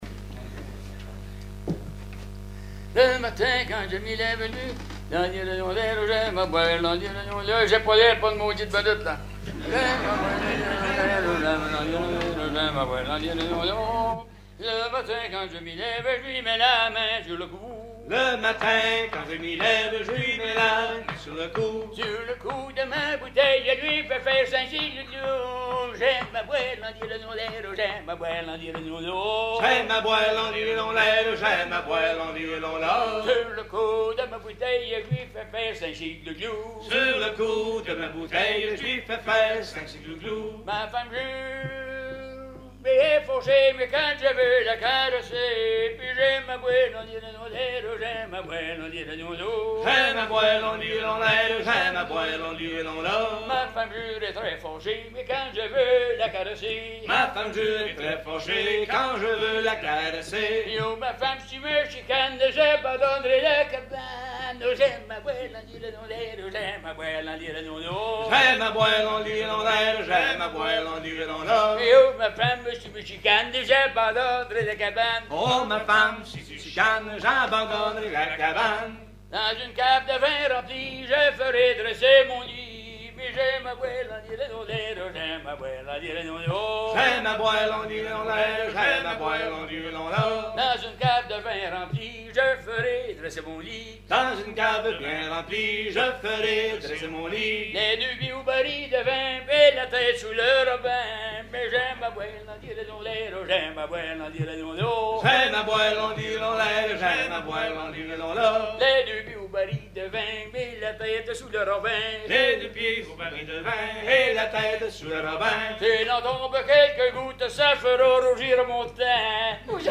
circonstance : bachique
Genre strophique
Concert à la ferme du Vasais
Pièce musicale inédite